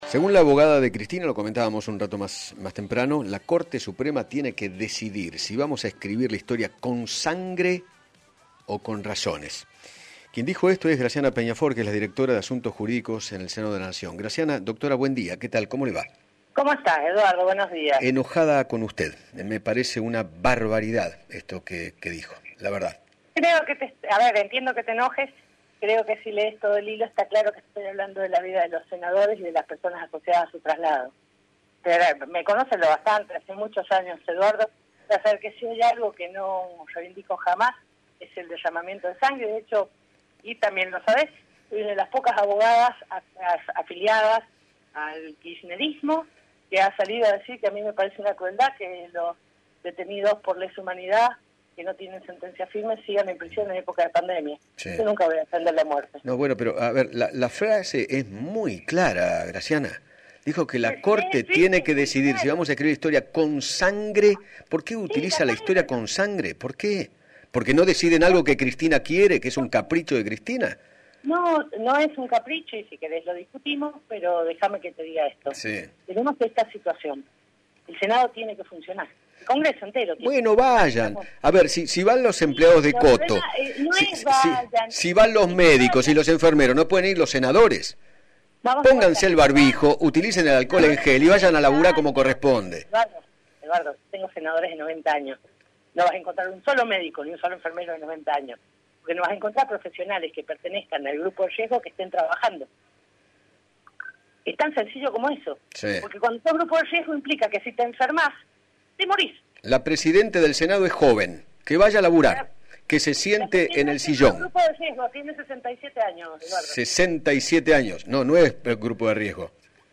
Graciana Peñafort, directora de Asuntos Jurídicos en el Senado y abogada defensora de Cristina Fernández, dialogó con Eduardo Feinmann sobre sus polémicas declaraciones en Twitter, donde advirtió que ” la Corte Suprema tiene que decidir si los argentinos vamos a escribir la historia con sangre o con razones”.